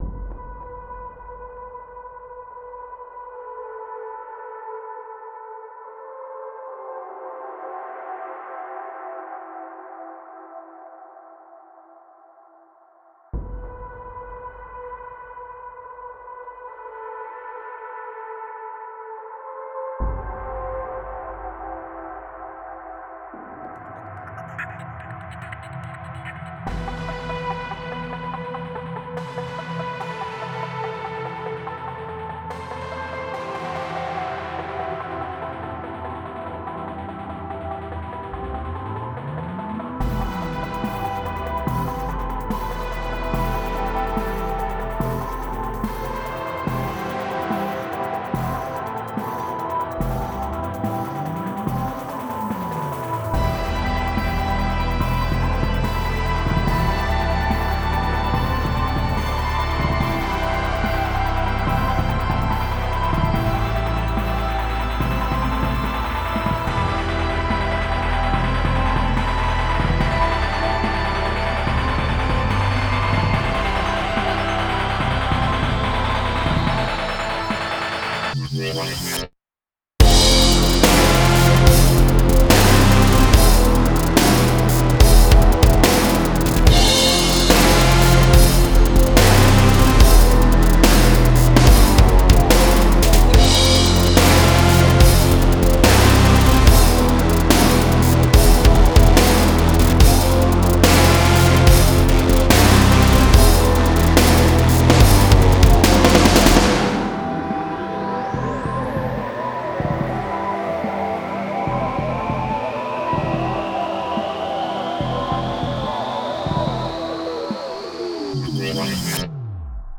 Sections: 1:20-1st Drop, 1:46-Breakdonw, 2:00-2nd Drop